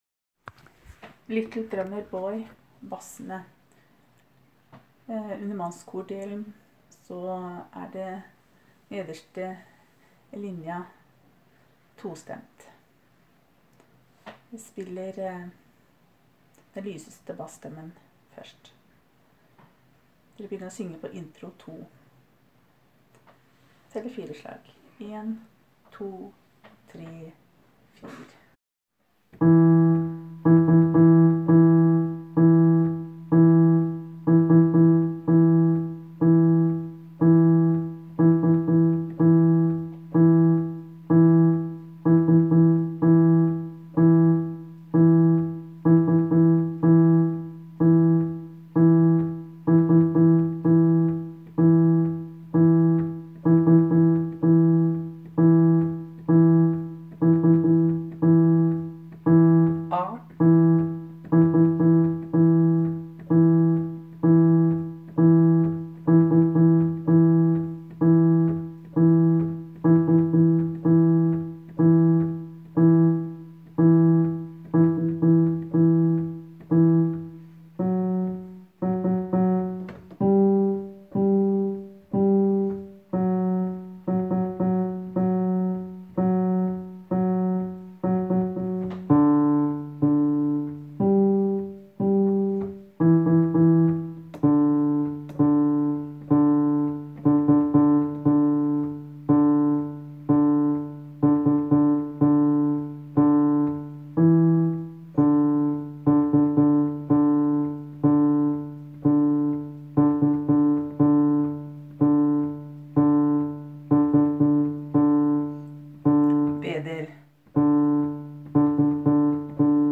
Jul 2017 Bass (begge konserter)
Little-Drummer-Boy-Bassene-Linje-2-lyseste-tonen.m4a